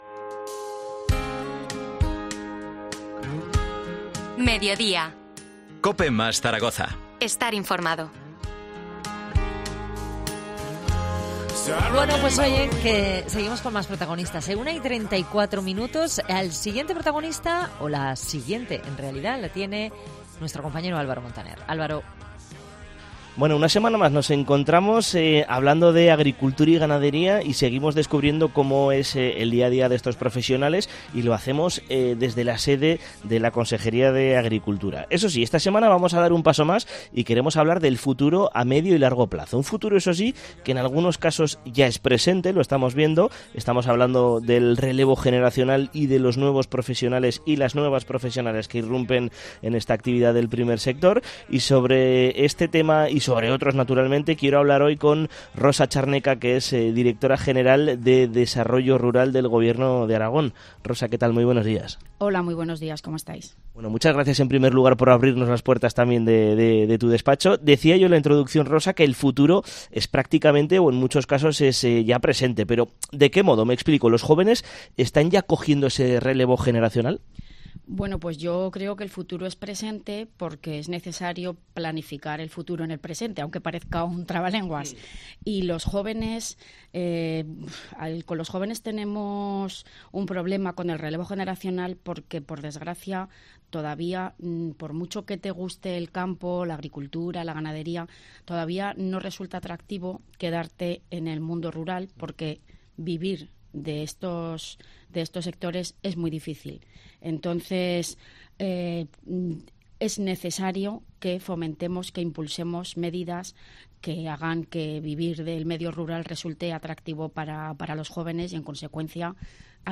Entrevista a Rosa Charneca, Directora General de Desarrollo Rural del Gobierno de Aragón